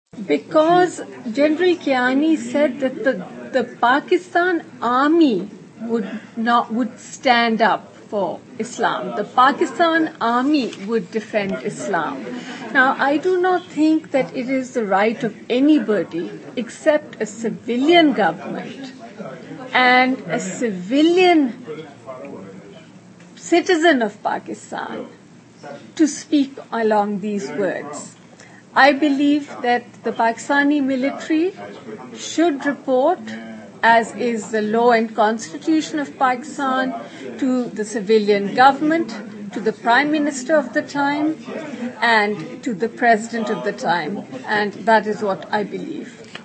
د میرمن اصفهاني نه مې بل تپوس دا اوکړو ( د انتخاباتو په دې اخري پړاؤ کې د جرنیل کيانی دغه بیان څه معنی لري چې وائی - اسلام د پاکستان روح دی ؟-